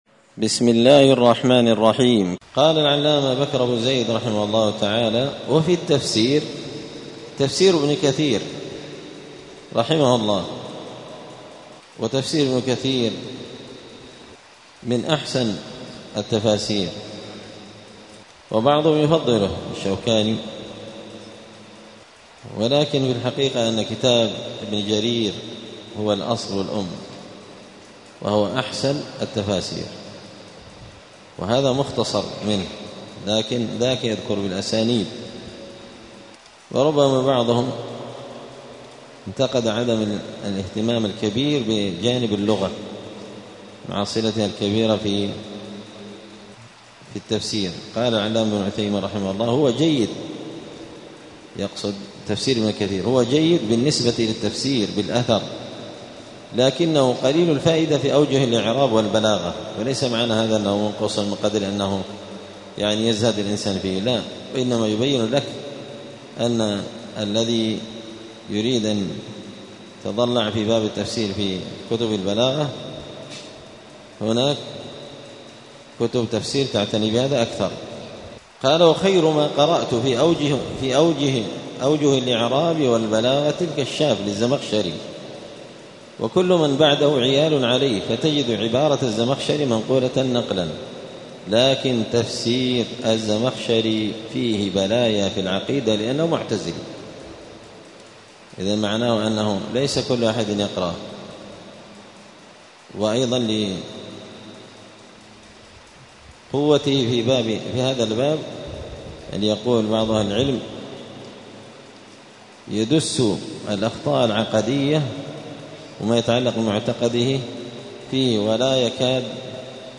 *الدرس الثالث والعشرون (23) {فصل كيفية الطلب والتلقي اختيار أهمية التفسير}*